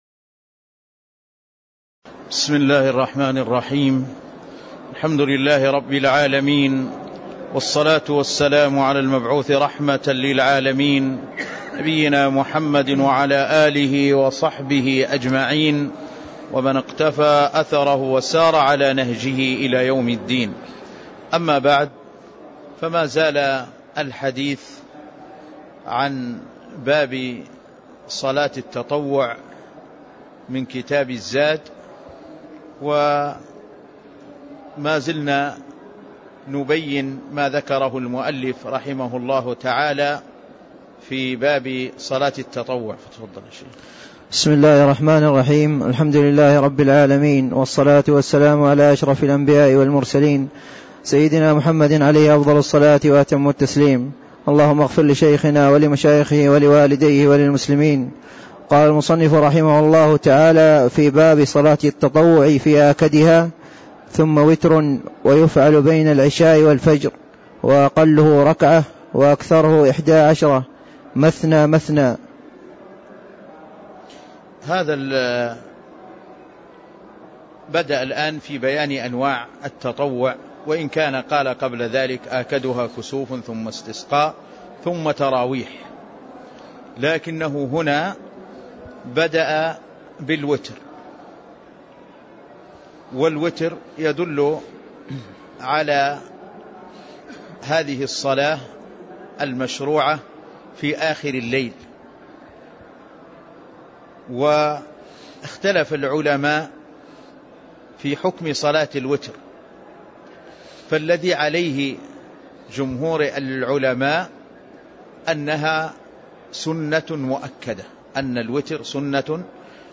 تاريخ النشر ١٨ محرم ١٤٣٦ هـ المكان: المسجد النبوي الشيخ